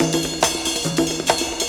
Jazz Loop 141.wav